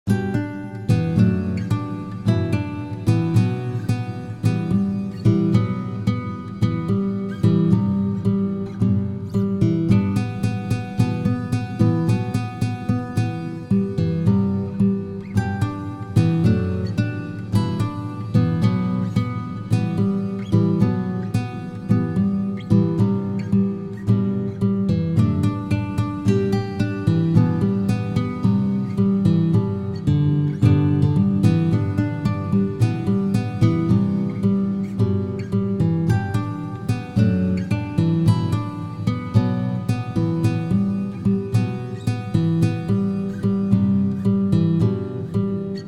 Arrangements for solo guitar of the